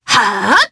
Selene-Vox_Casting3_jp_b.wav